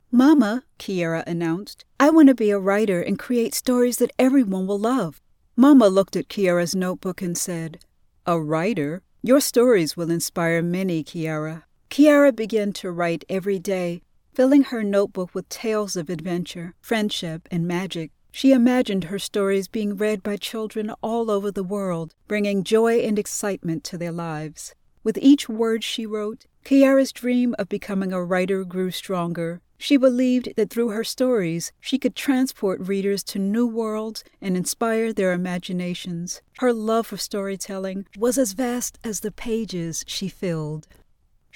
Kiera's Adventure_Audiobook Narration
Middle Aged
Female voice talent with a warm, engaging tone, skilled at delivering diverse styles - from friendly commercial ads to authoritative explainer videos.
Experienced in recording from a dedicated home studio, providing quick turnaround times and excellent audio quality.
Kieara's_Adventure_Audiobook_Audition.mp3